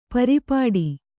♪ pari pāḍi